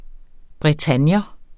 Udtale [ bʁεˈtanjʌ ]